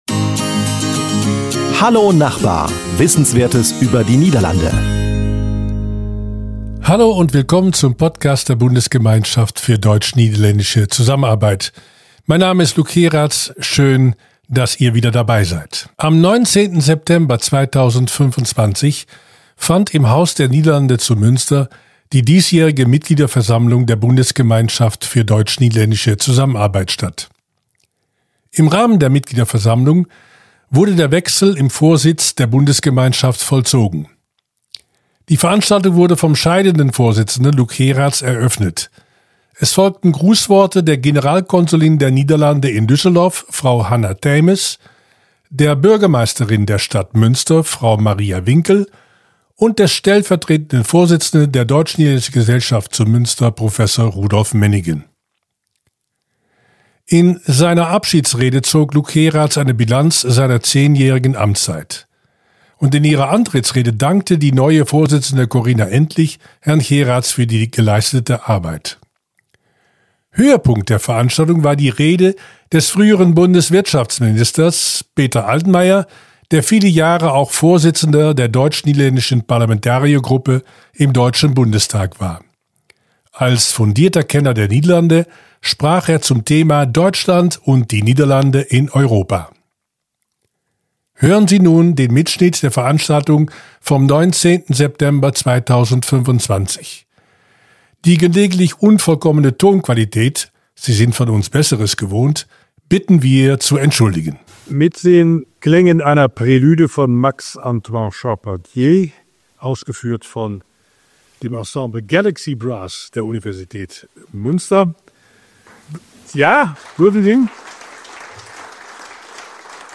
Der frühere Bundeswirtschaftsminister Peter Altmaier hielt am 19. September 2025 im Rahmen der Mitgliederversammlung der Bundesgemeinschaft für deutsch-niederländische Zusammenarbeit einen Festvortrag zum Thema Deutschland und die Niederlande in Europa.
Hören Sie einen Mittschnitt der Veranstaltung vom 19. Seoptember 2025 im Haus der Niederlande zu Münster.